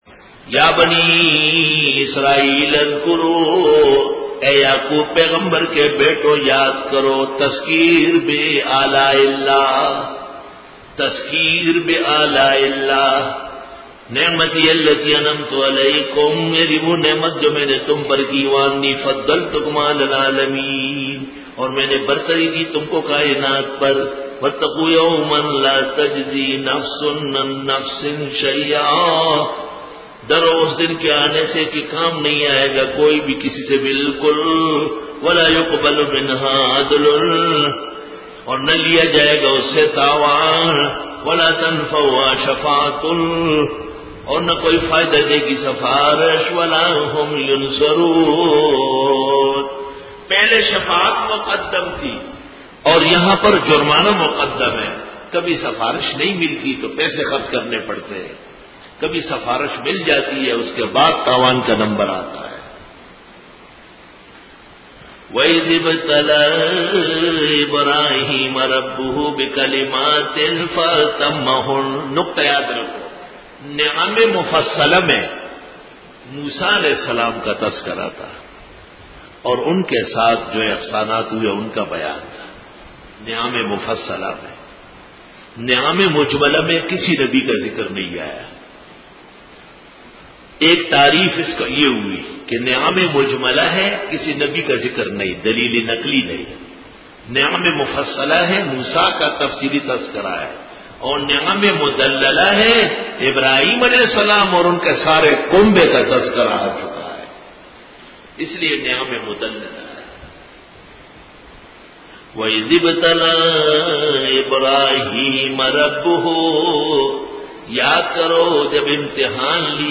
سورۃ البقرۃ رکوع-15 Bayan